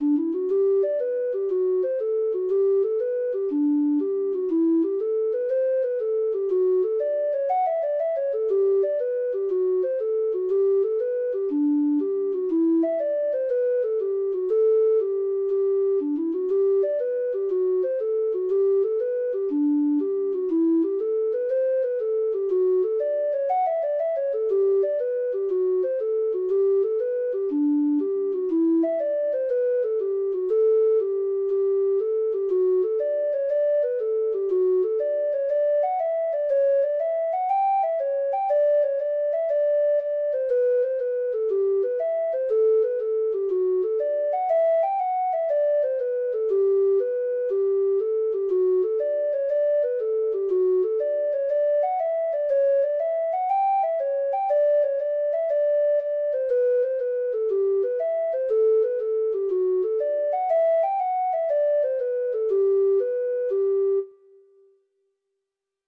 Traditional Music of unknown author.
Hornpipes